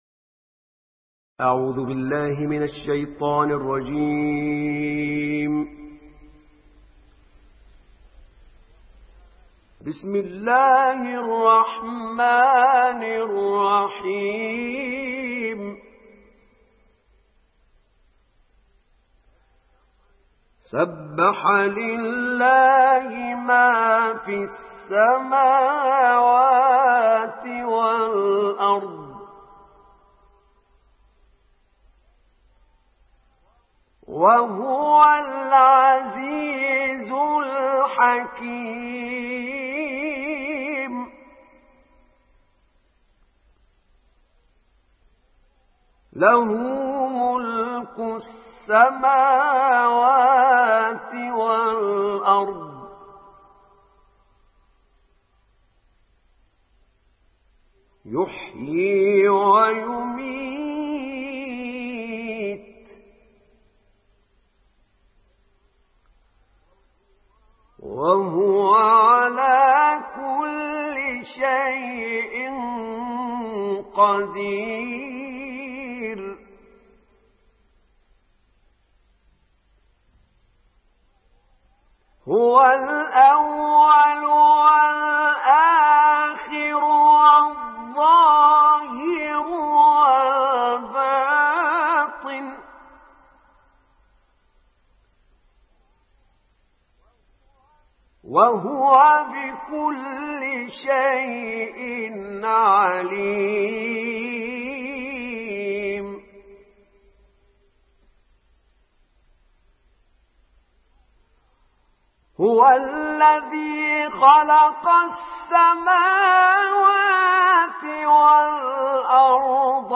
تلاوت مجلسی کوتاه
تلاوت کوتاه مجلسی احمد نعینع از آیات 1 تا 6 سوره حدید به مدت 4 دقیقه و 12 ثانیه